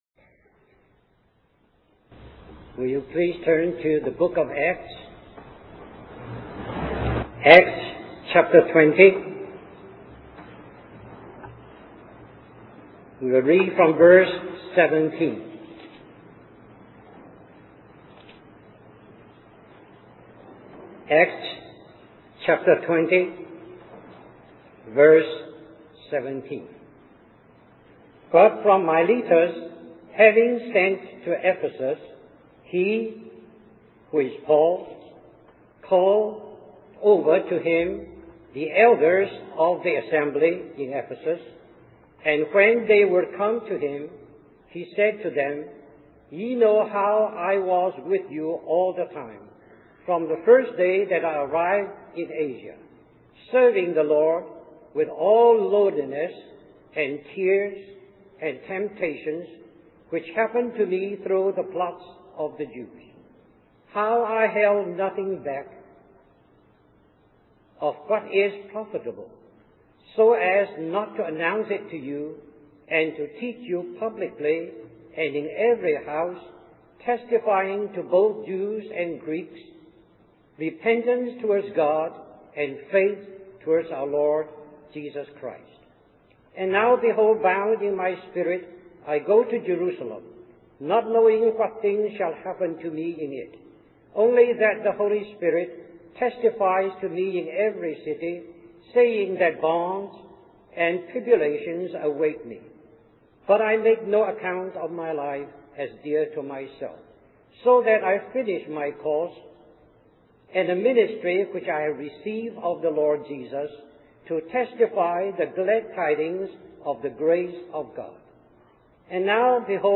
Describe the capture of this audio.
1996 Christian Family Conference